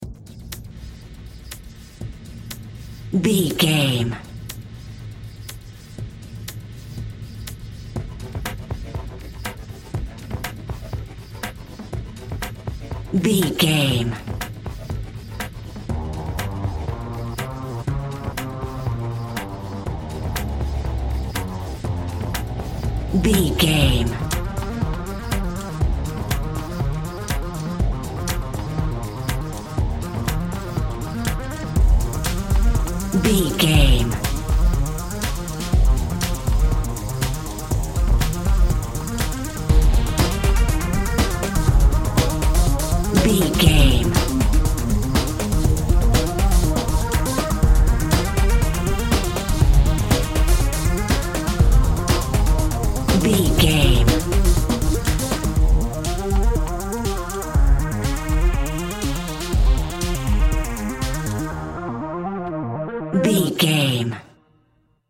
Music and Electric Sounds.
In-crescendo
Ionian/Major
E♭
groovy
futuristic
energetic
driving
drum machine
synthesiser
electronic
techno
trance
industrial
glitch
synth lead
synth bass